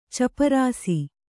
♪ caparāsi